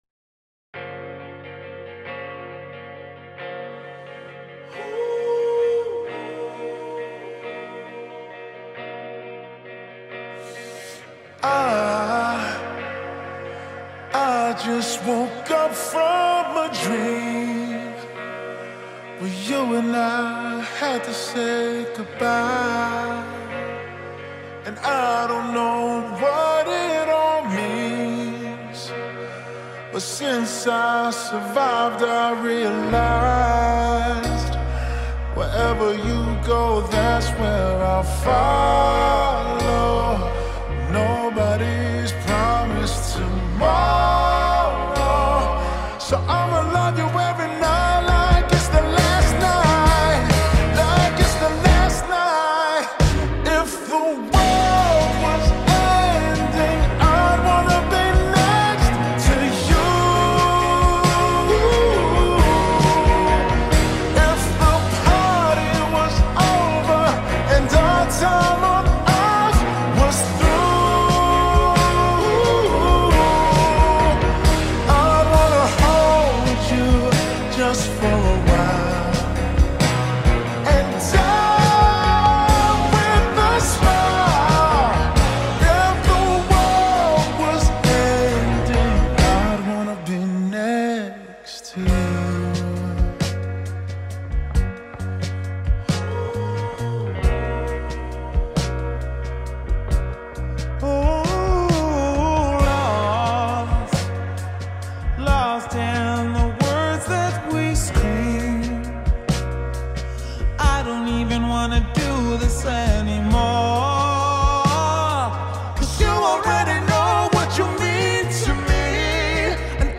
نسخه کند شده و Slowed